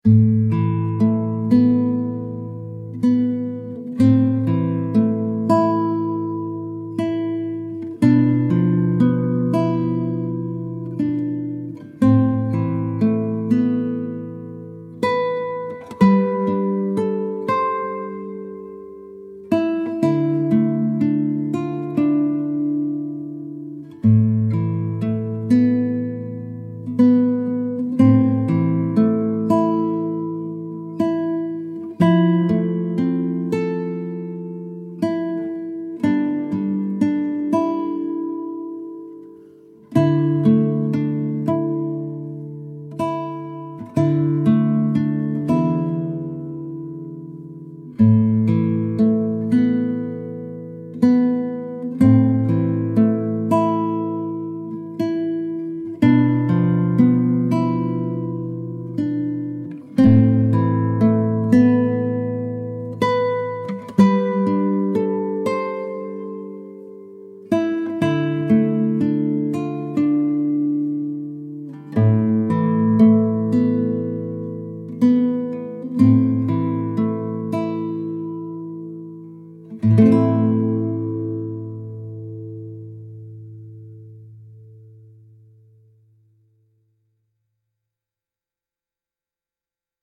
solo classical guitar piece with deep emotion and deliberate phrasing